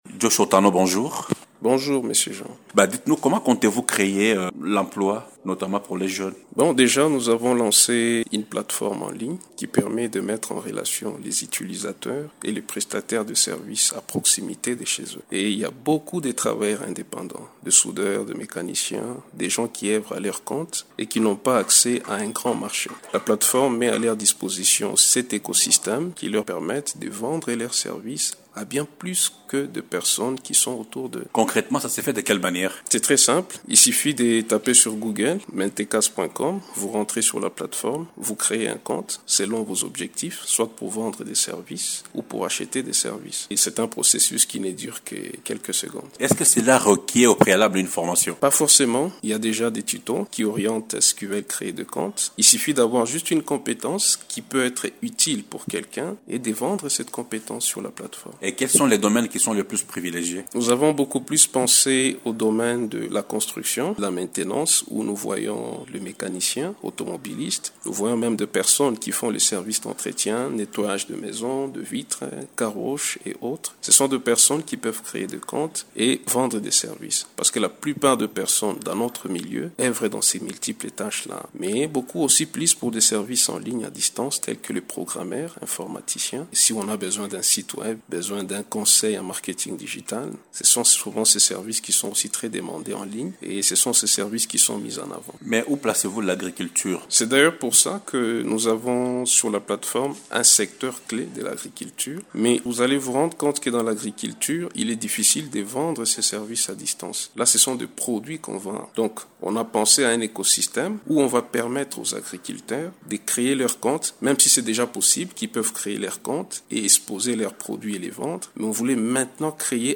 lors d’un entretien à Radio Okapi samedi 22 mars.